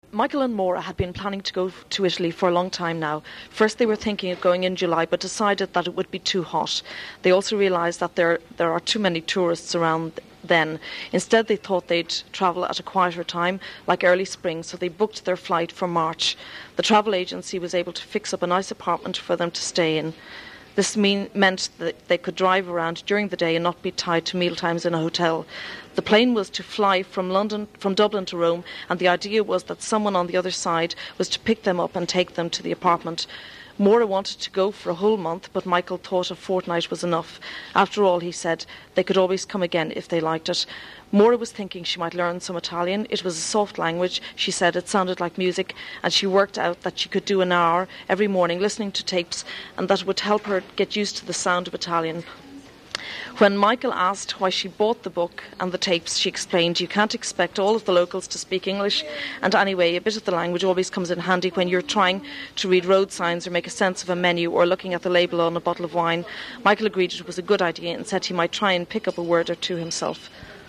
Dublin English, new
In the past ten to fifteen years the pronunciation of English in the capital Dublin has undergone considerable changes. Noticeable in this respect is the raising of low back vowels, e.g. bought [bɔ:ṱ], and the retraction of diphthongs, e.g. time [tɑɪm], and the use of a retroflex r, e.g. card [kɑ:ɻd], as well as a velarised l in syllable-final position, e.g. field [fi:ɫd].
Ireland_Dublin_Fashionable.wav